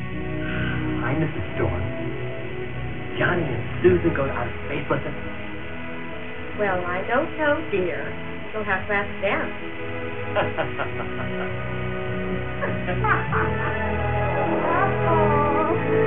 Hi Mrs. Storm, can Johnny and Susan go to outer space with us?” in a voice that I would never have expected from a man of his dimensions, while he and Mrs. Storm share 7th Heaven expressions while laughing like utter buffoons.